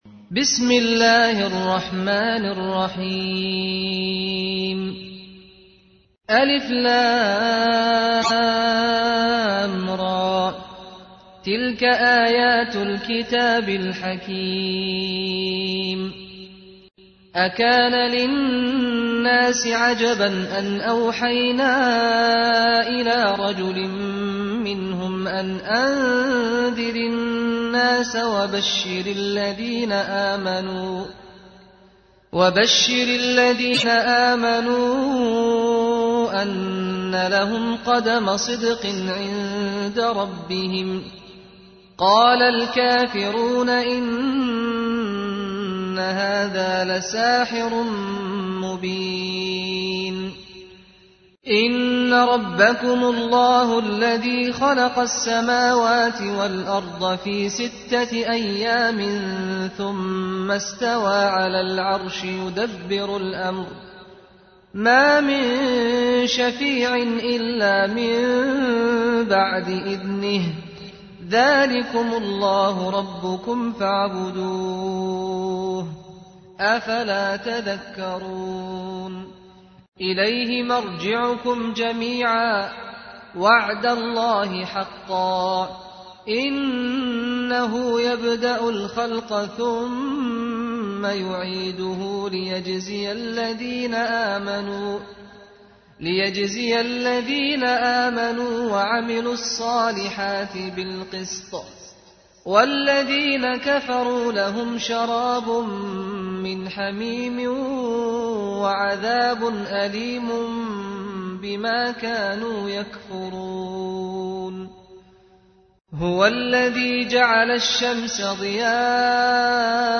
ترتیل سوره یونس با صدای استاد سعد الغامدی